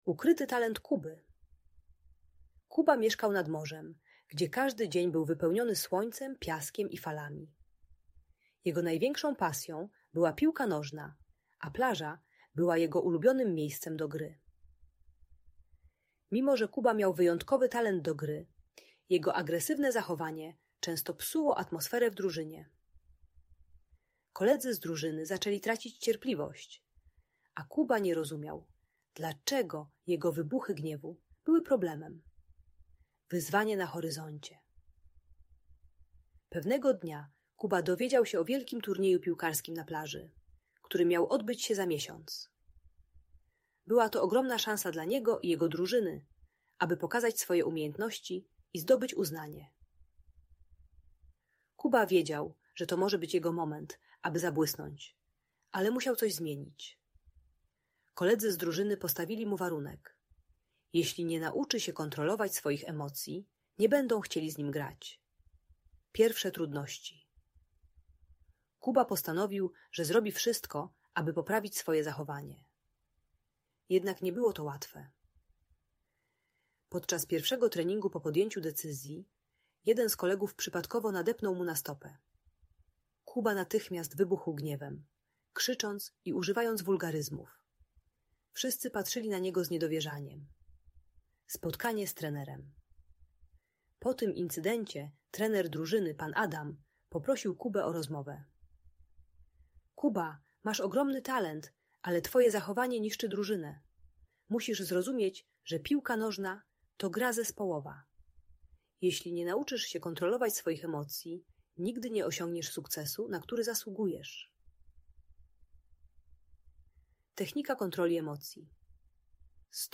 Ukryty Talent Kuby - Audiobajka